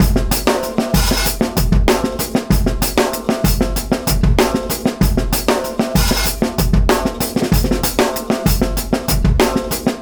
Track 14 - Drum Break 02.wav